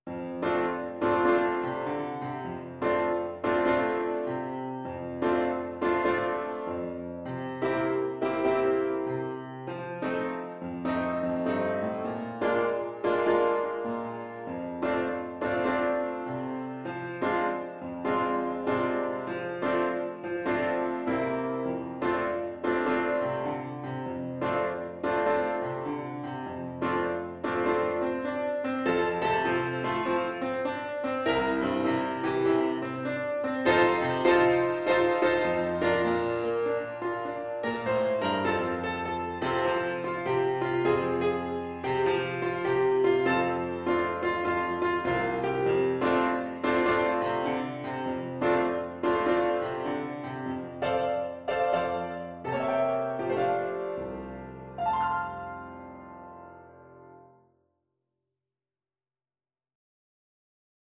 4/4 (View more 4/4 Music)
Moderate swing